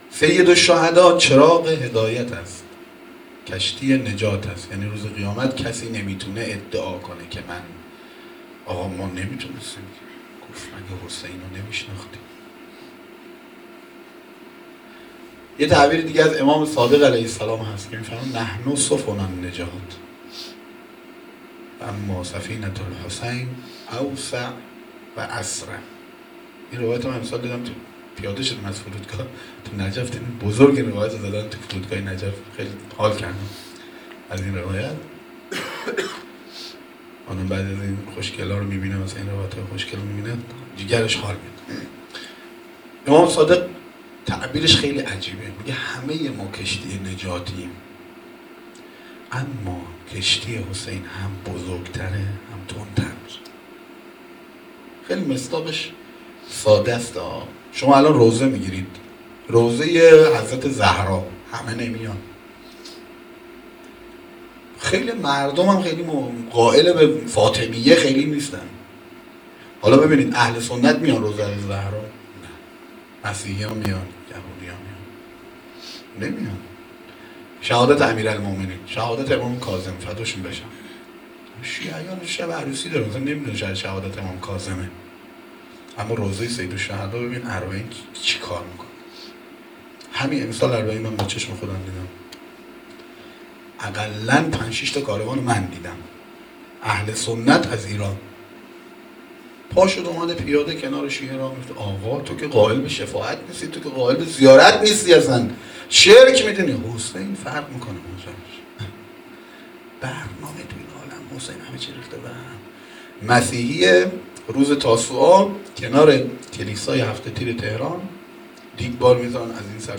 سخنرانی - بخش1.m4a